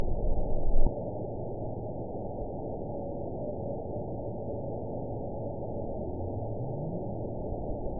event 910535 date 01/22/22 time 01:43:39 GMT (3 years, 3 months ago) score 9.55 location TSS-AB01 detected by nrw target species NRW annotations +NRW Spectrogram: Frequency (kHz) vs. Time (s) audio not available .wav